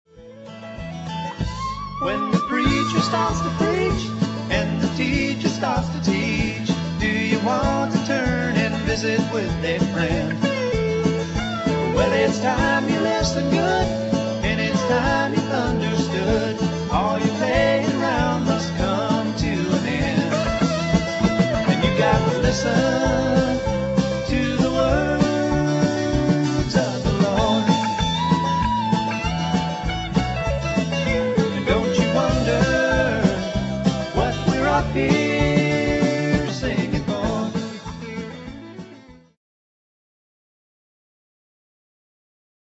Keyboards